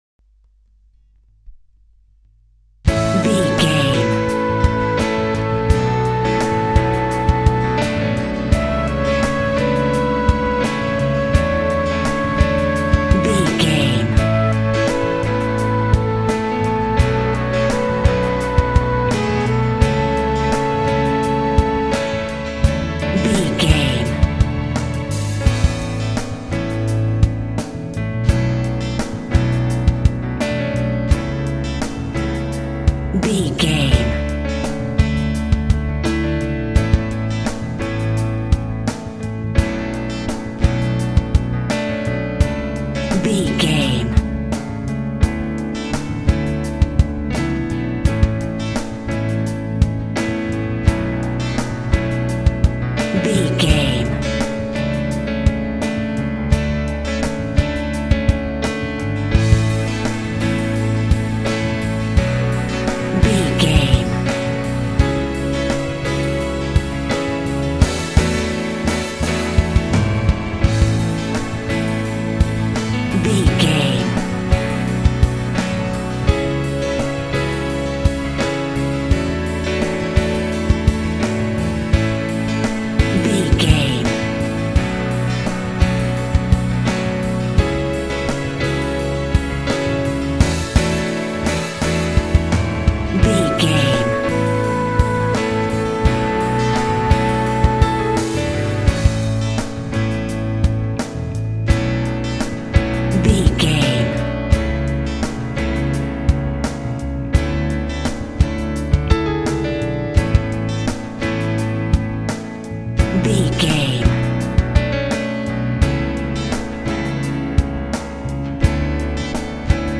Ballad on the Flute.
Ionian/Major
pop
cheesy
pop rock
drums
bass guitar
electric guitar
piano
hammond organ